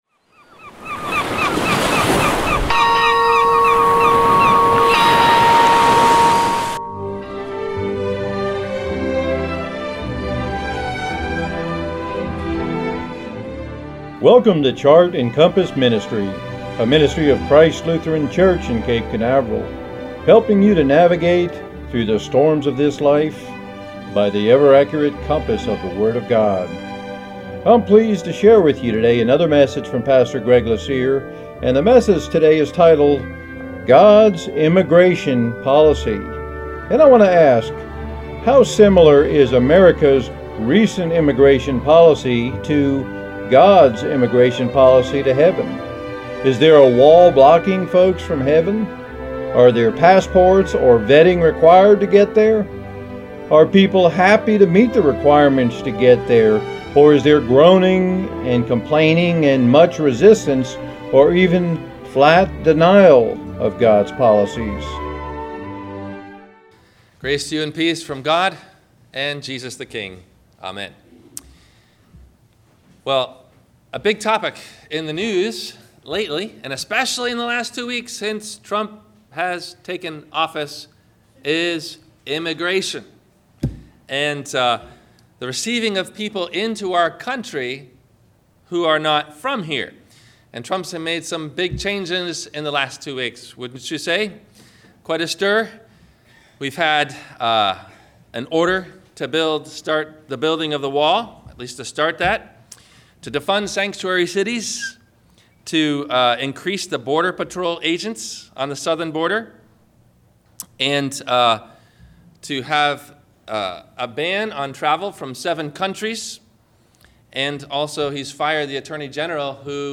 Questions asked before the Sermon message: